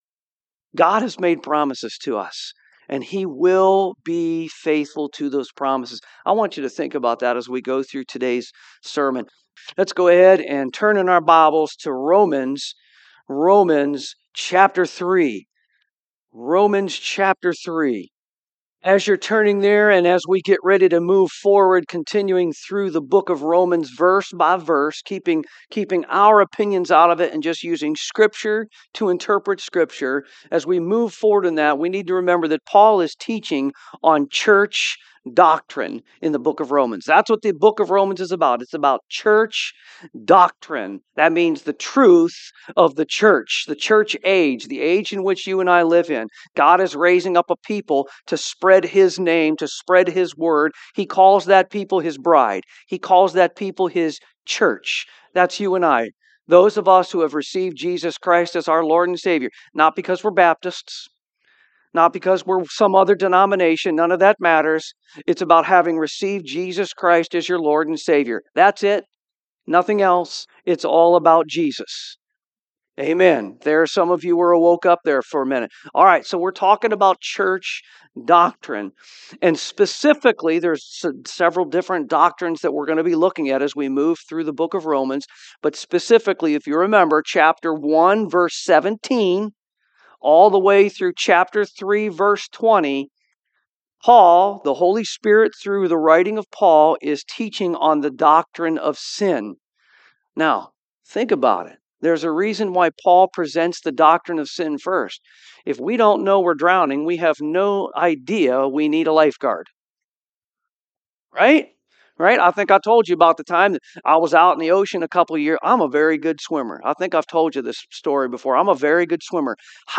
Romans 3:1-8 Service Type: AM All People